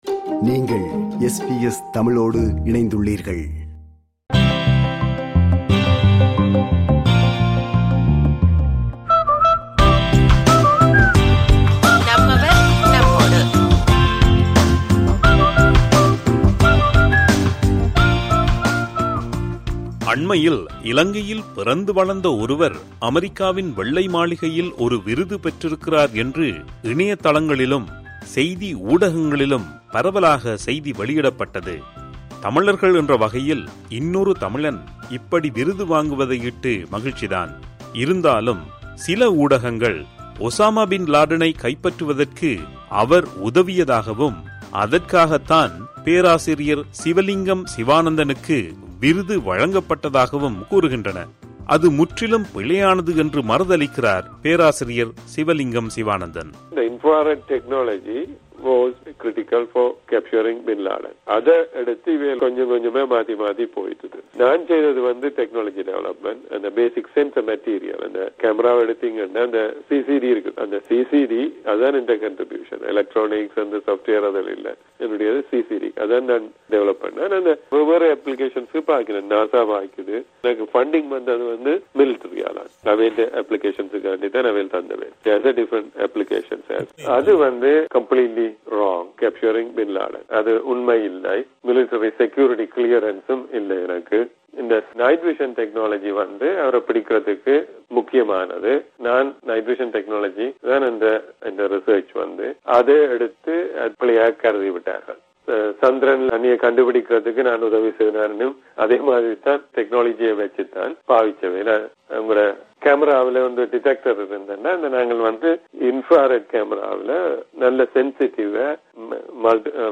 in this extensive interview feature